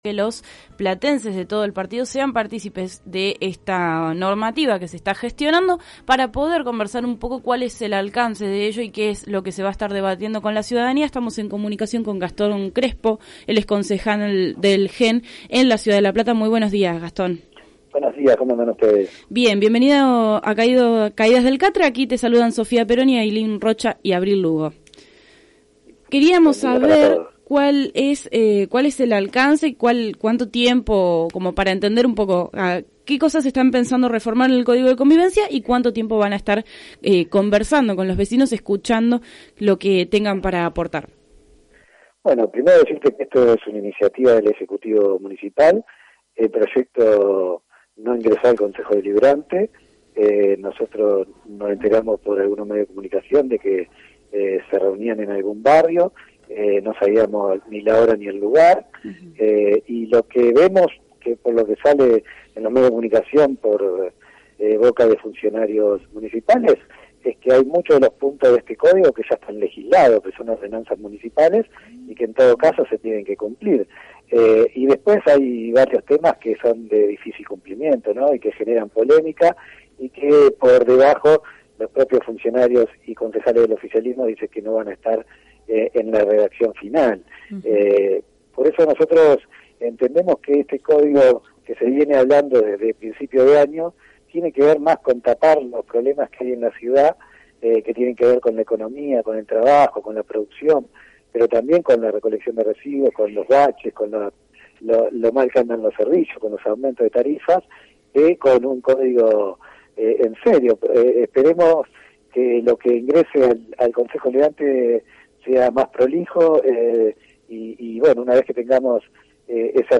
(11-10-18) A raíz del impulso desde el municipio de talleres con vecinos de La Plata para debatir el nuevo código de convivencia, Caídas del Catre conversó con el concejal por el GEN, Gastón Crespo, sobre el proyecto del ejecutivo.